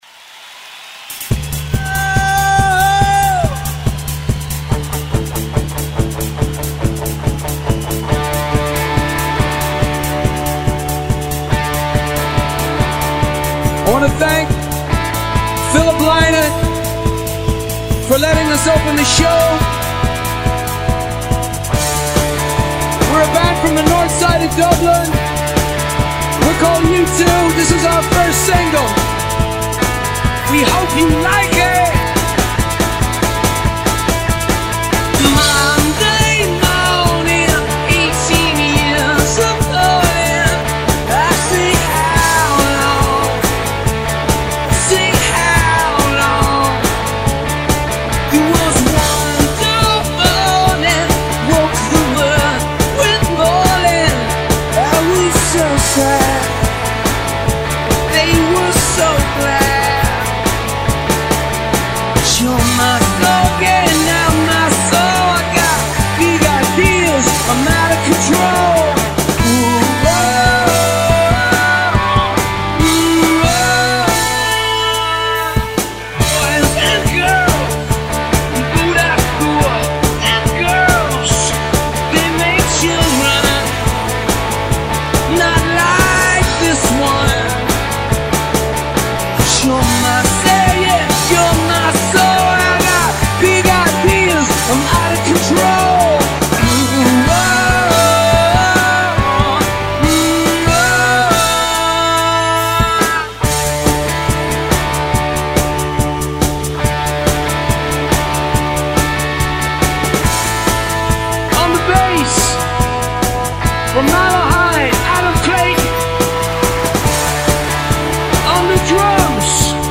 Multi-track recording